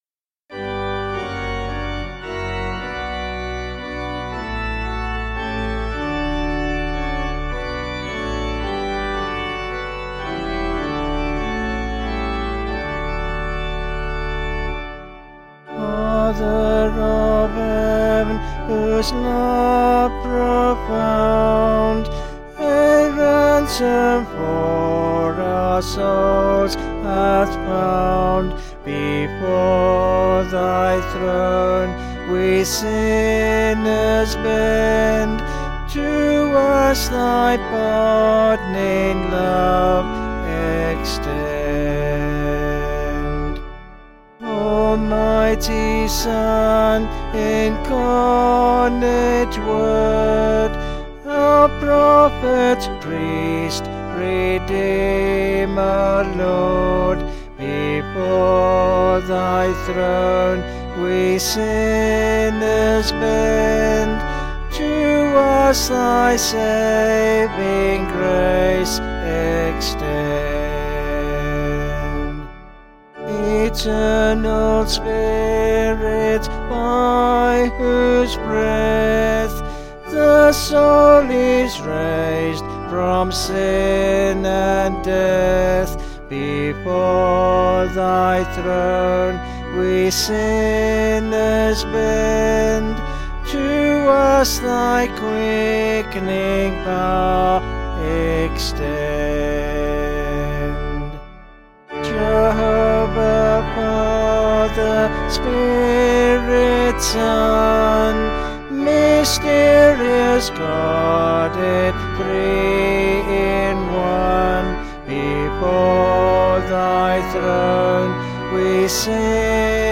Vocals and Organ   263.1kb Sung Lyrics